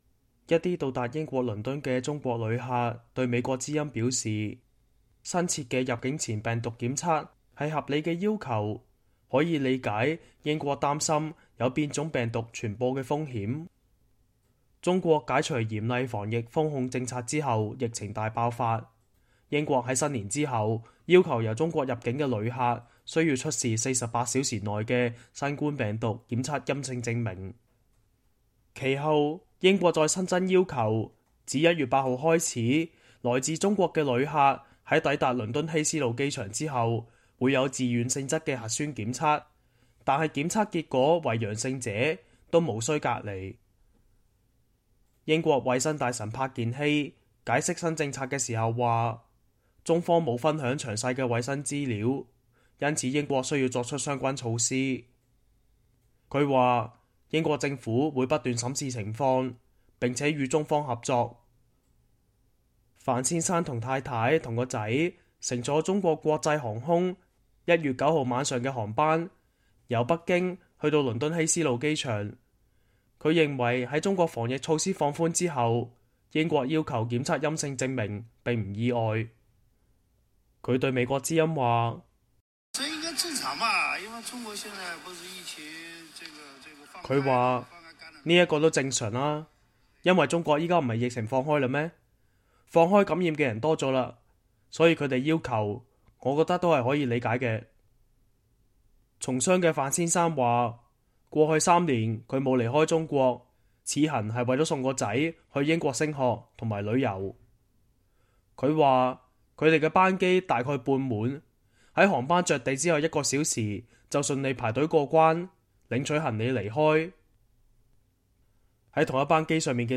一些到達英國倫敦的中國旅客對美國之音表示，新設的入境前病毒檢測是合理的要求，可以理解英國擔心有變種病毒傳播的風險。